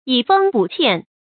以豐補歉 注音： ㄧˇ ㄈㄥ ㄅㄨˇ ㄑㄧㄢˋ 讀音讀法： 意思解釋： 把豐年積余的糧食儲備起來，留待災年歉收時使用。